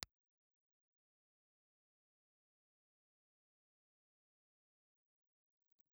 Ribbon
Impulse Response File:
Impulse Response file of the Rauland W-1247 ribbon microphone.
Rauland_W1247_IR.wav